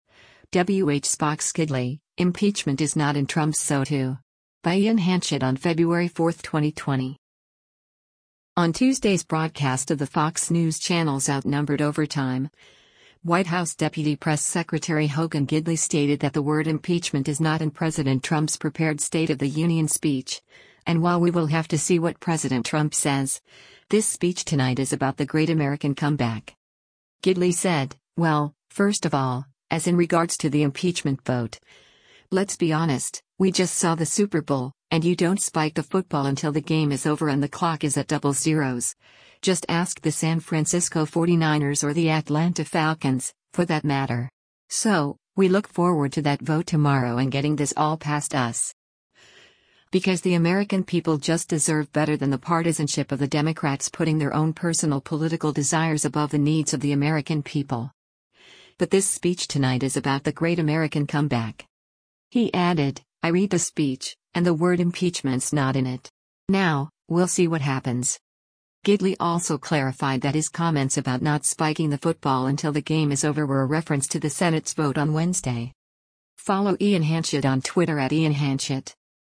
On Tuesday’s broadcast of the Fox News Channel’s “Outnumbered Overtime,” White House Deputy Press Secretary Hogan Gidley stated that the word “impeachment” is not in President Trump’s prepared State of the Union speech, and while we will have to see what President Trump says, “this speech tonight is about the great American comeback.”